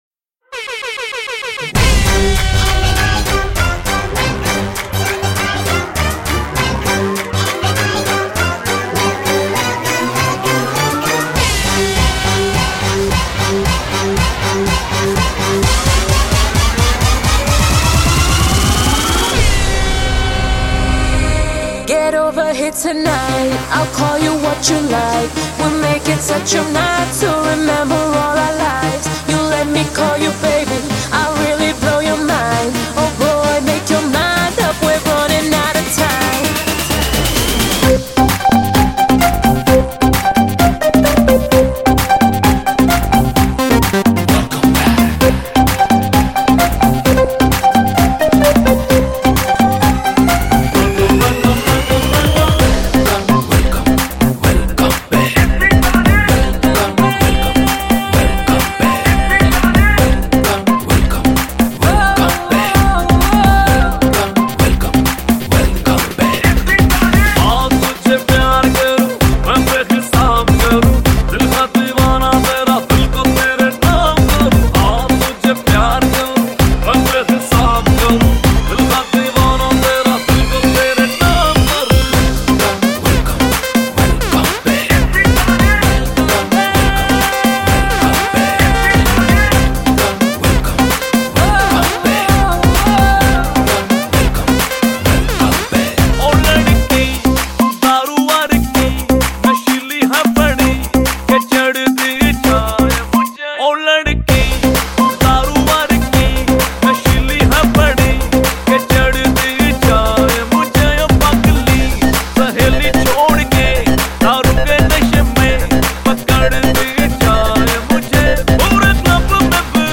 Bollywood Mp3 Music 2015